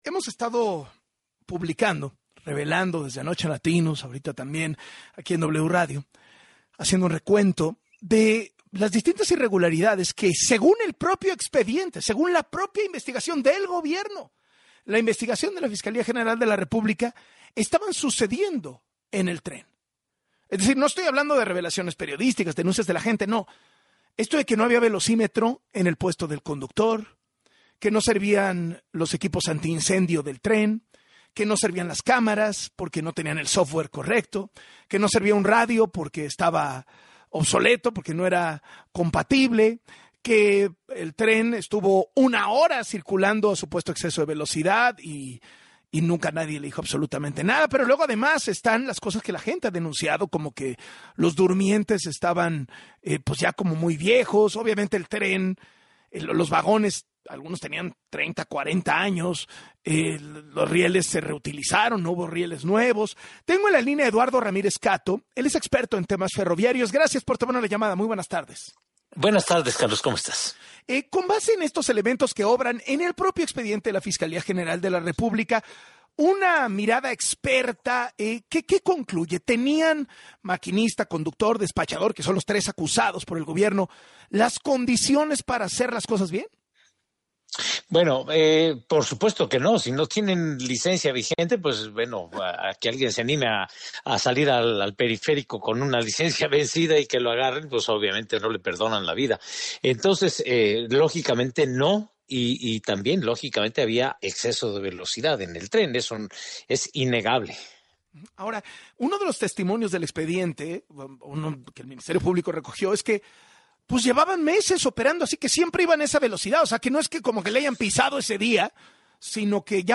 En entrevista con Carlos Loret de Mola